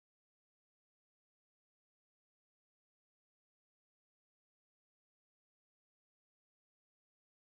Night_128 – Stack_Metal_1
bass house construction kit drops